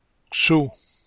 In the case of the letter X, the pronunciation is somewhat similar to the syllables that are containing S, but starting from a position of the tongue joined to the palate, making it sound more strong: